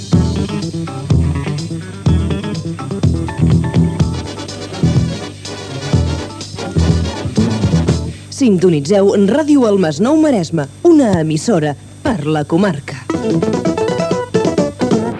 bbcc991fd5f82e52115eb46737a1b4dea0dc99e5.mp3 Títol Ràdio El Masnou Maresme Emissora Ràdio El Masnou Maresme Cadena Cadena 13 Titularitat Privada nacional Descripció Identificació de l'emissora.